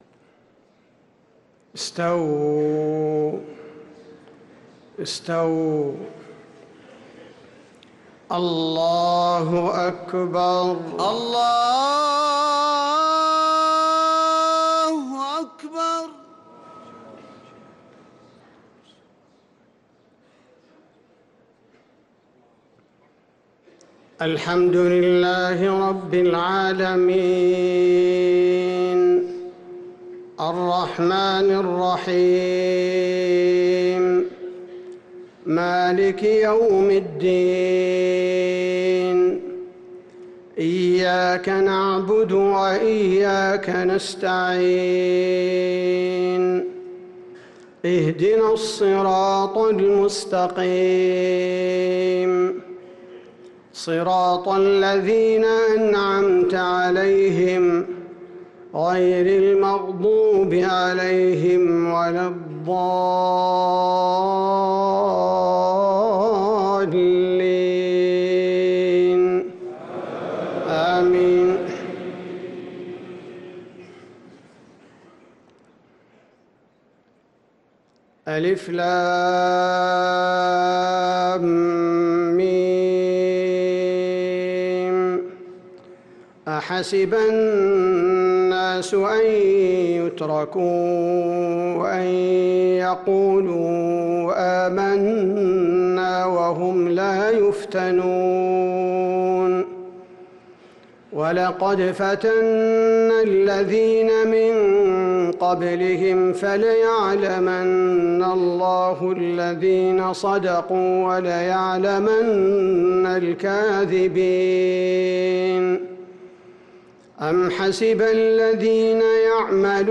صلاة الفجر للقارئ عبدالباري الثبيتي 10 رمضان 1445 هـ